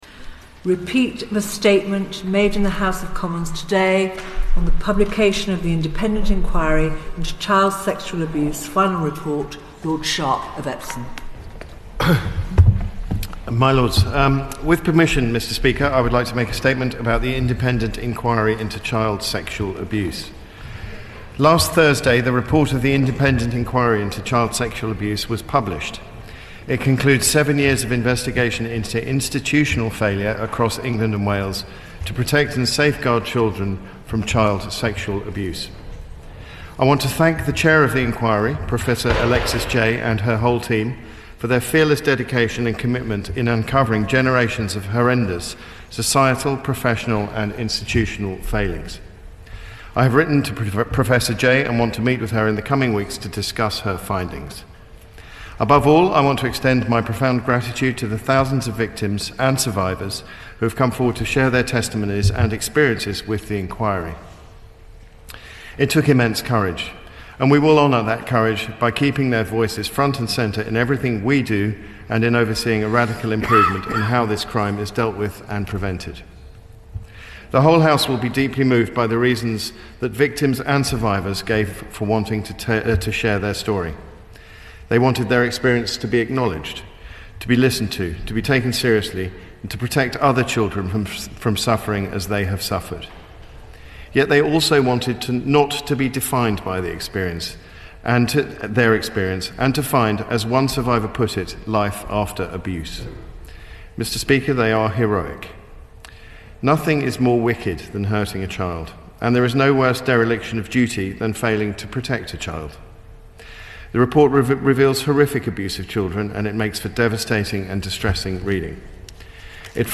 Lord Sharpe repeating a statement by the Home Sec (du jour) made earlier in the HoC. The debate that follows starts at 6.56. and contains some interesting input about mandatory reporting.